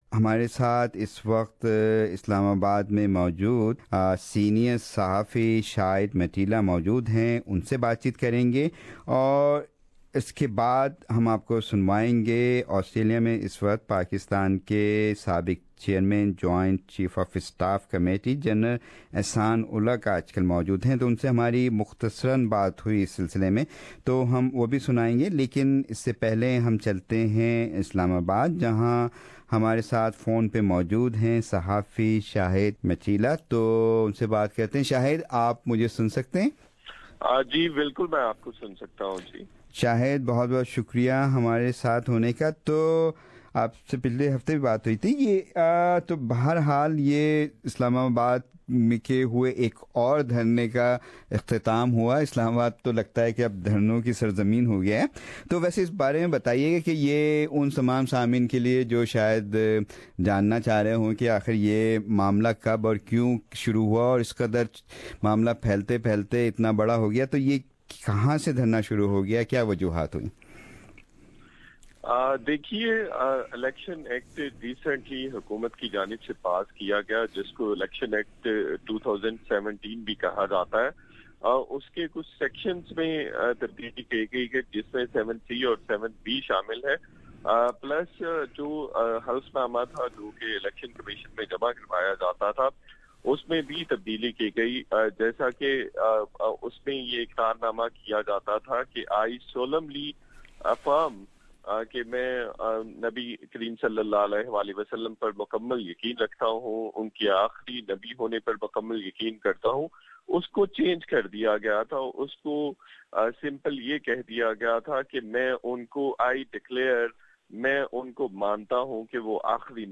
We also talked to former Chief of Pakistan’s spy agency, Inter-Services Intelligence (ISI) General (R) Ehsan Ul Haq about the relationship between the army and civilian governments in Pakistan.